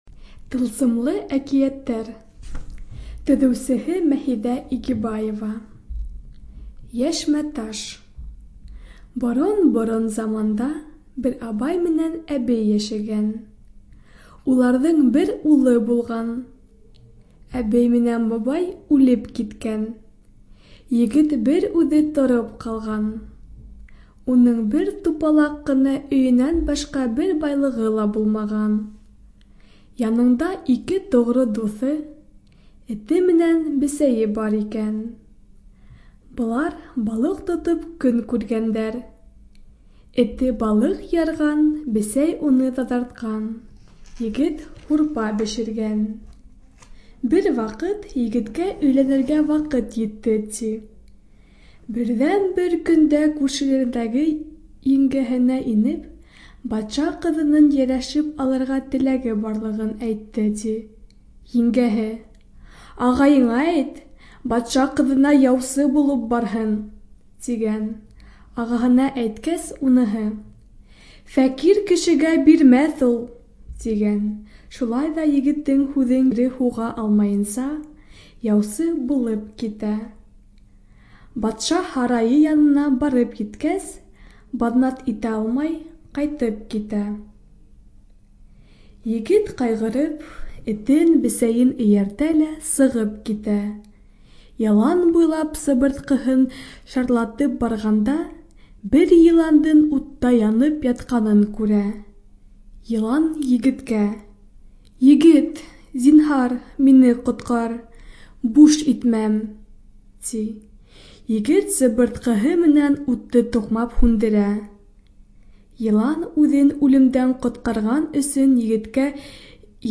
Студия звукозаписиБашкирская республиканская специальная библиотека для слепых